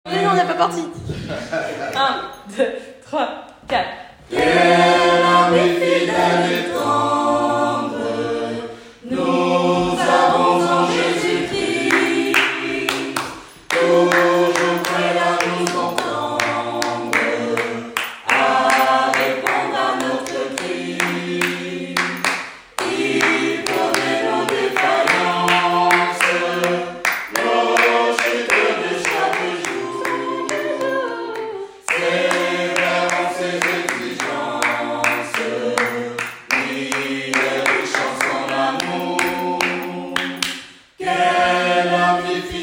Une chorale ouverte à tous et toutes sans exception.
Quelques extraits sonores de la chorale